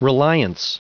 Prononciation du mot reliance en anglais (fichier audio)
Prononciation du mot : reliance